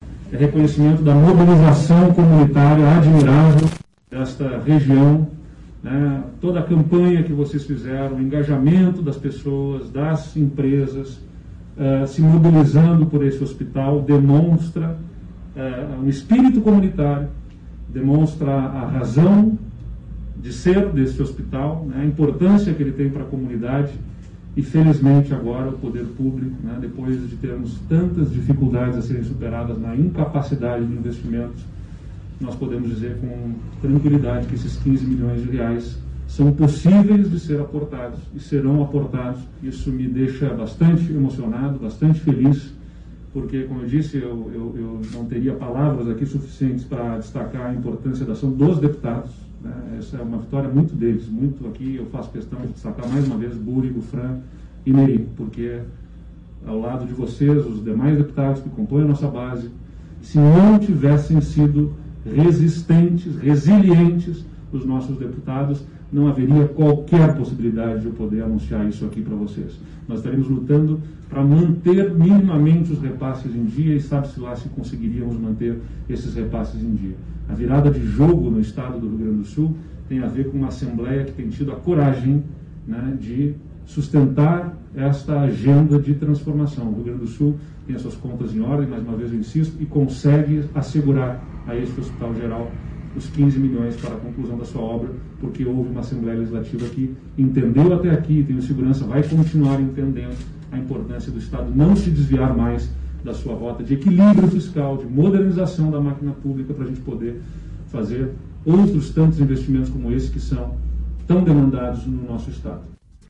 Em um pronunciamento, o governador Eduardo Leite agradeceu aos deputados estaduais Carlos Búrigo (MDB), Fran Somensi (Republicanos) e Neri, O Carteiro (Solidariedade), pelo trabalho intenso em procurar o governo para conseguir o repasse que faltava para a estrutura hospitalar.